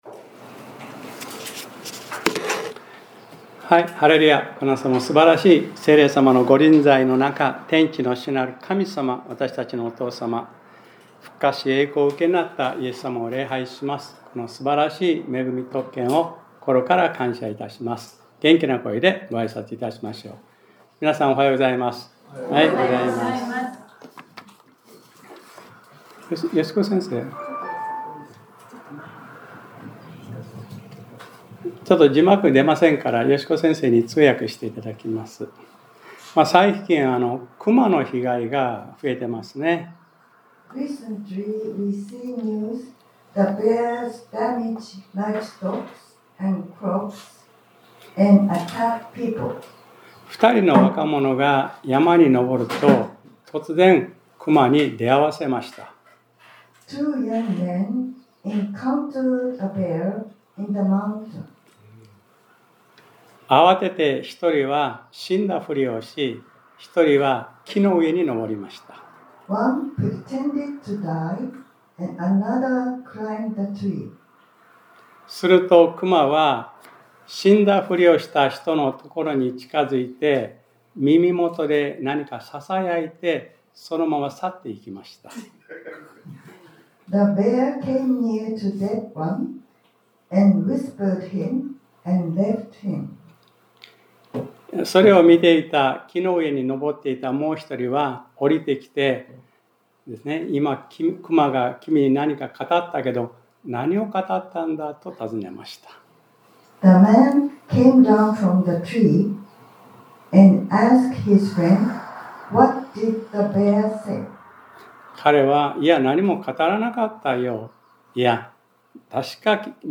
2025年10月26日（日）礼拝説教『 赦されない罪 』